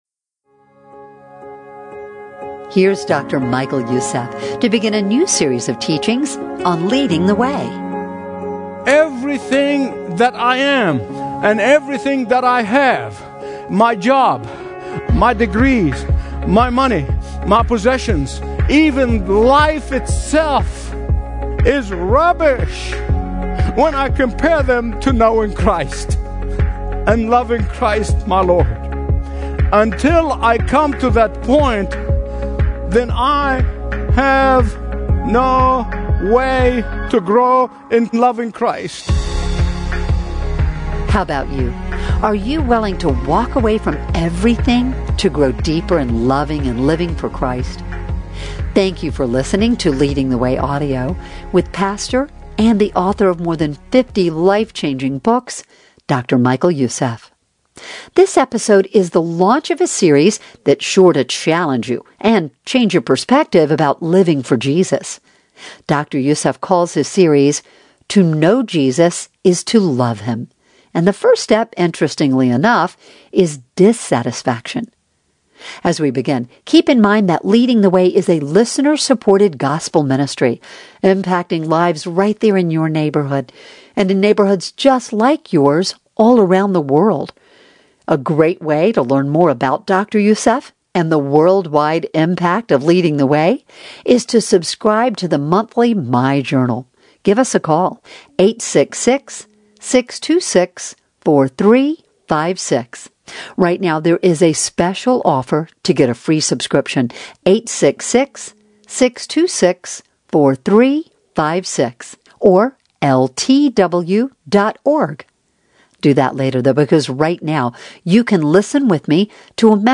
Stream Expository Bible Teaching & Understand the Bible Like Never Before